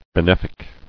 [be·nef·ic]